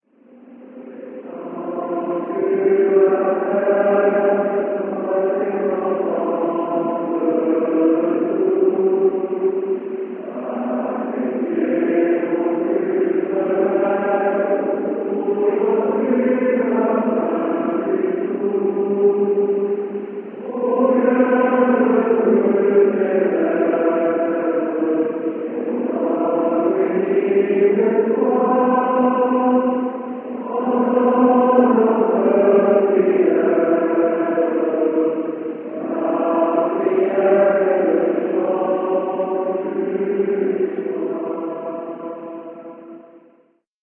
31° anniversaire de l'église Notre-Dame de Jamhour
enregistrement à l'église du Collège (114k)